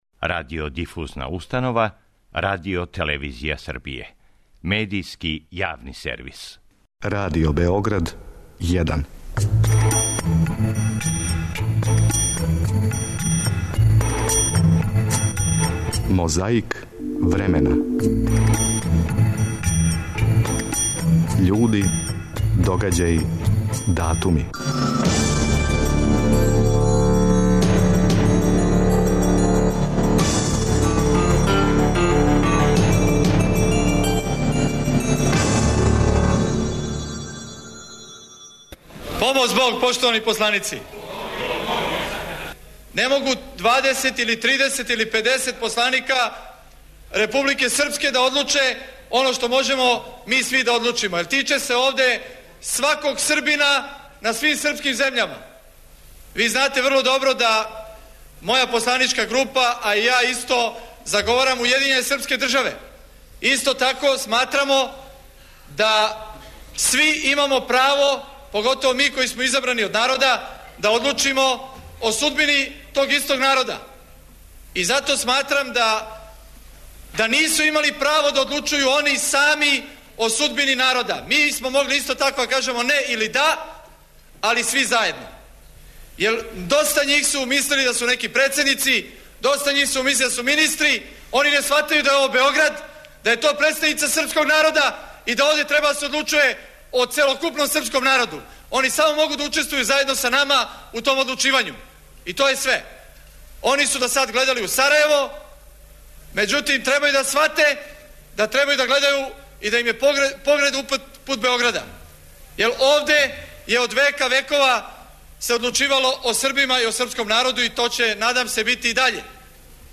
Звучна коцкица нас враћа на 28. април 1993. године када је на заседању Народне скупштине говорио Жељко Ражнатовић Аркан.